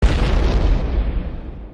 Explosion 1.mp3